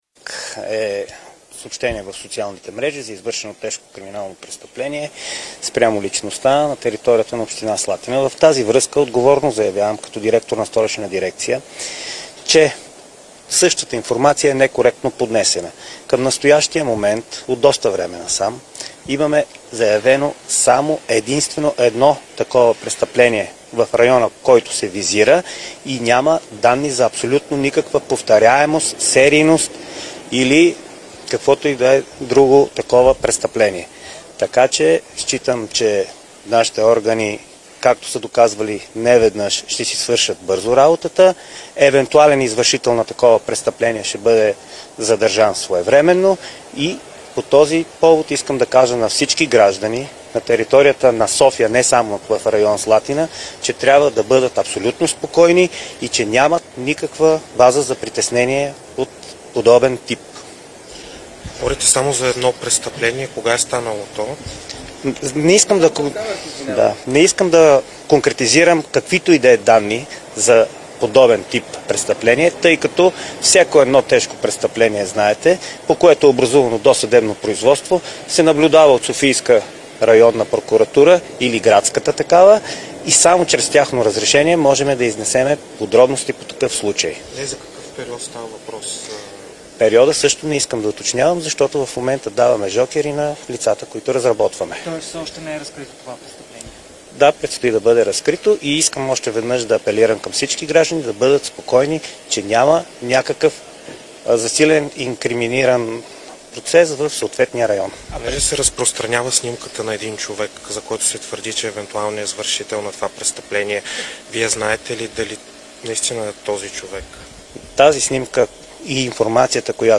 14.10 - Брифинг на кмета на София Васил Терзиев във връзка с проверка на готовността за зимно почистване. - директно от мястото на събитието (София)
Директно от мястото на събитието